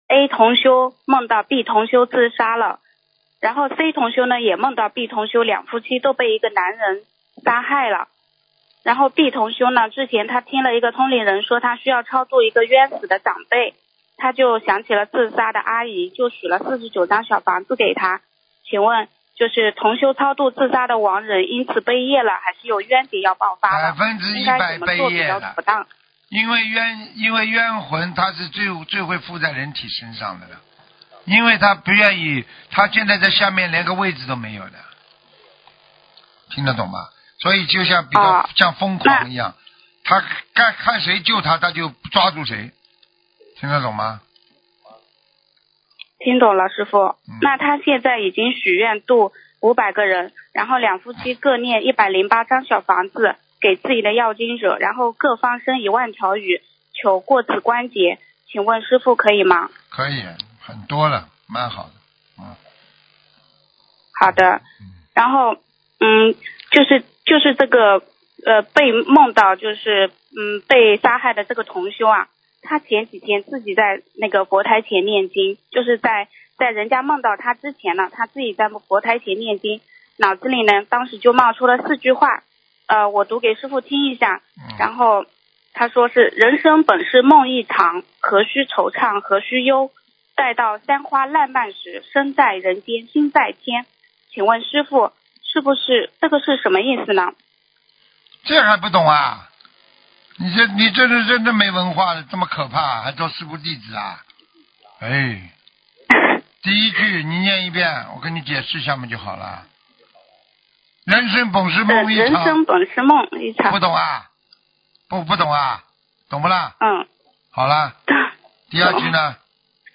女听众